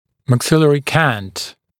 [mæk’sɪlərɪ kænt][мэк’силэри кэнт]наклон верхнечелюстной плоскости